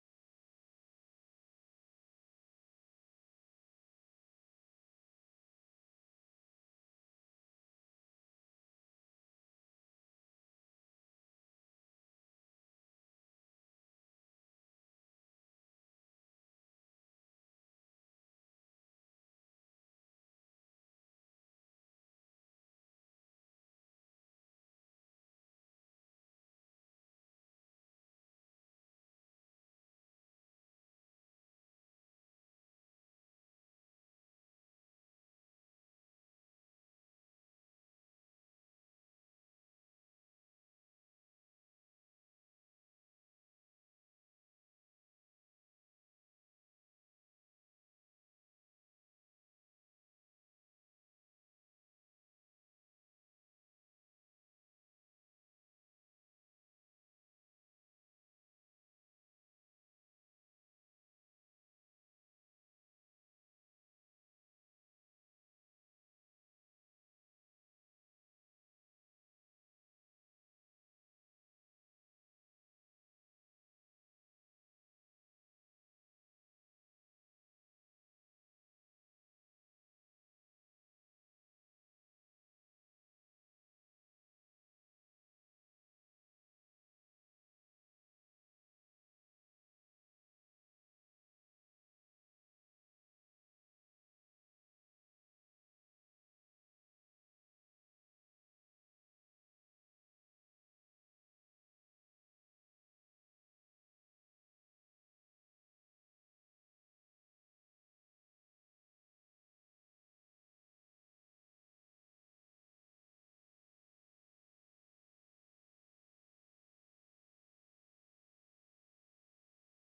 Agenda Nieuwegein - Avond van de Raad Digitaal vanuit zaal 008 donderdag 22 oktober 2020 20:00 - 22:00 - iBabs Publieksportaal
De vergadering wordt digitaal gehouden gezien de aangescherpte maatregelen.